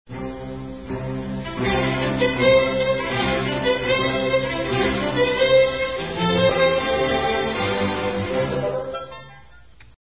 The strings in the symphony heard